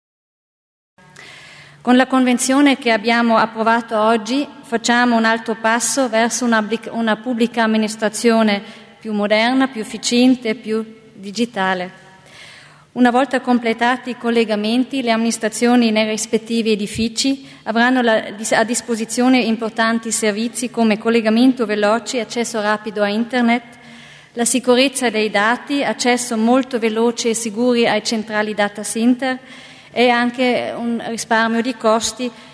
L'Assessore Deeg illustra i progetti in tema di banda larga